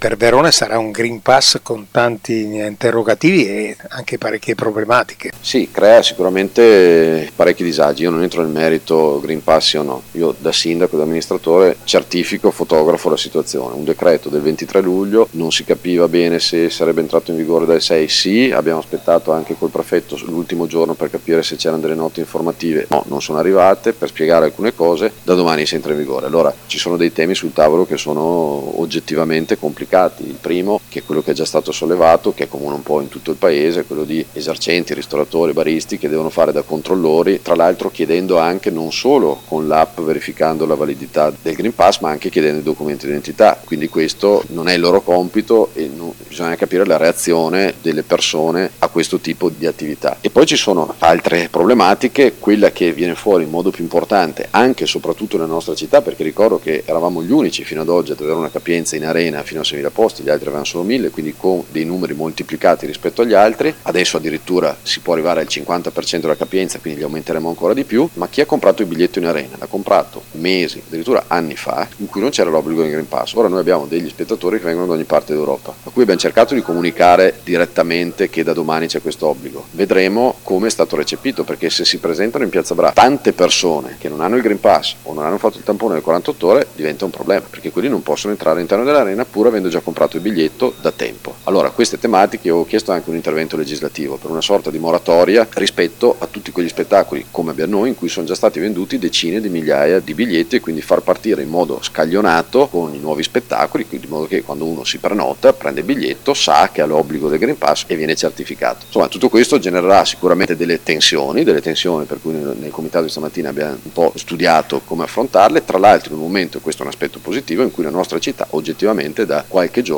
L’intervista
Sindaco-di-Verona-Federico-Sboarina-sul-Green-Pass.mp3